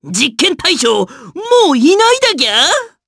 Lakrak-Vox_Victory_jp.wav